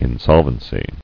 [in·sol·ven·cy]